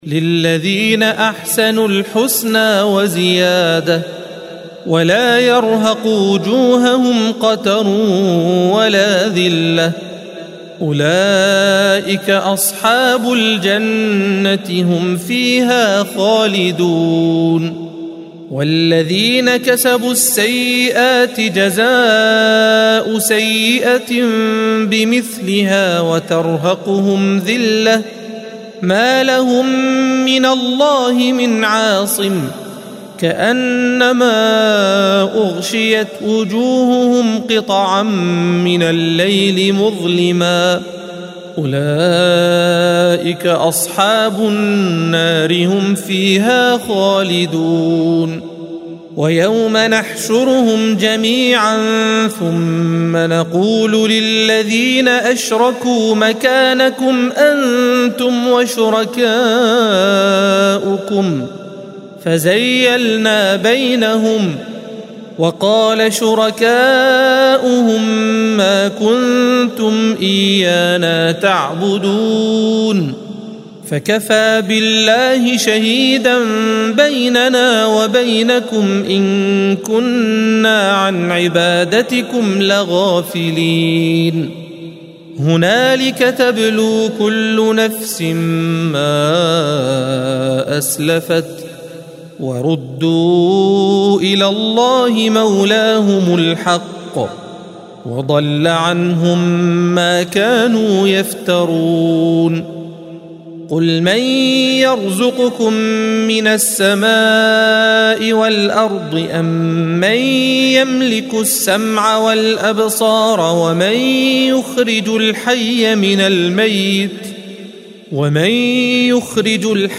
الصفحة 212 - القارئ